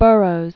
(bûrōz, bŭr-), Edgar Rice 1875-1950.